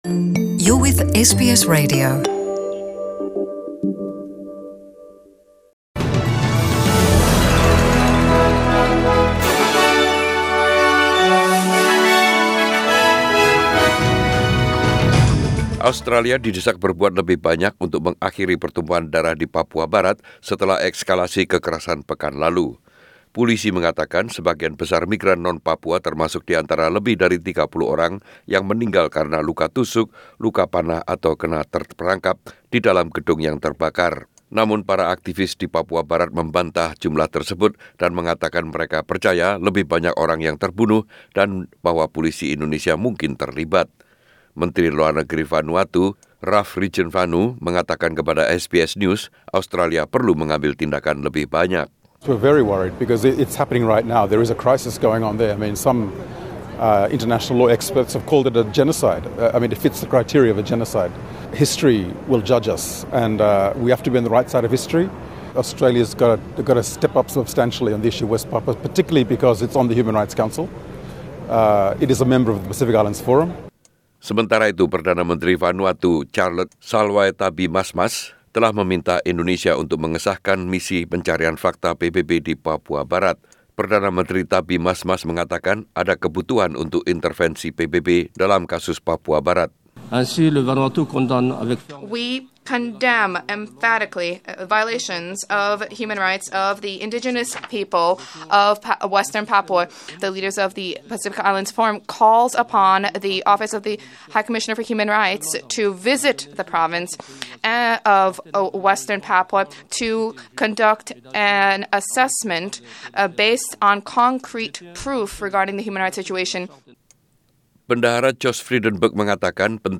SBS Radio News 29/09/2019 in Indonesian